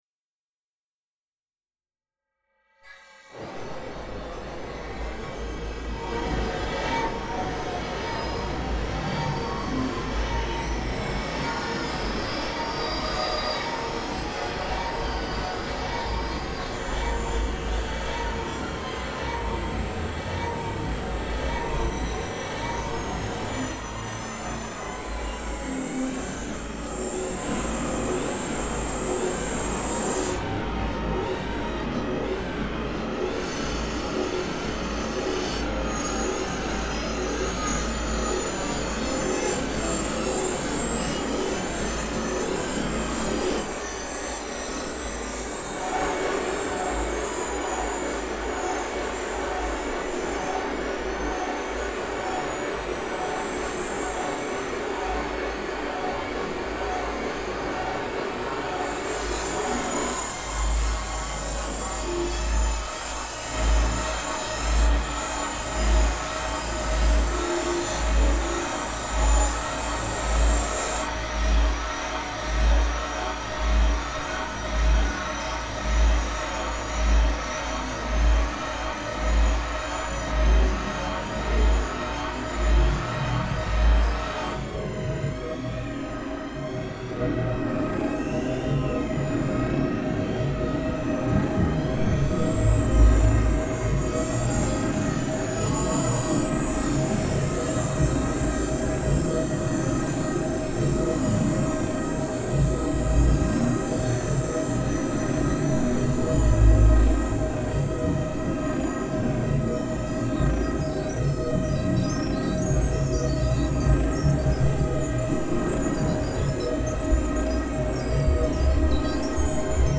A same opinion conversion. Harsh guttural sounds indeed, mirroring, reciprocally approving the harsh determination to not forget.